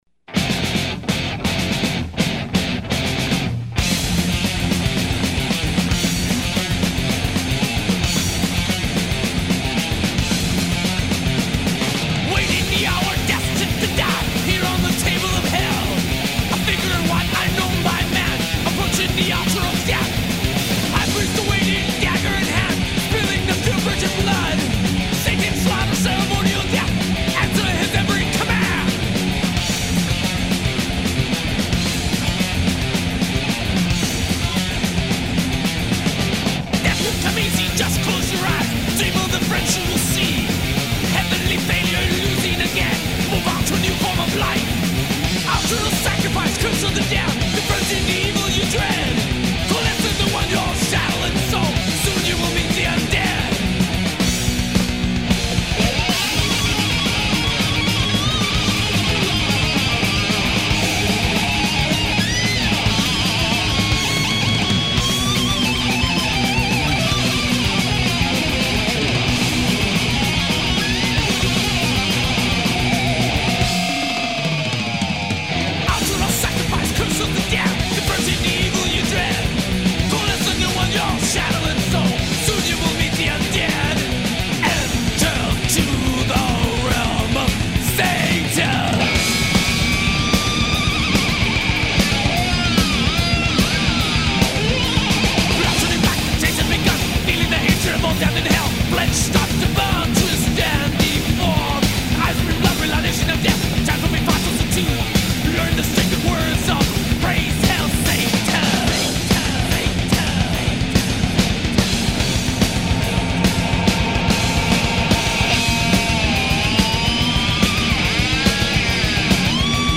In honor of the salad days of speed metal
I was always hypnotized by those monster, monster riffs.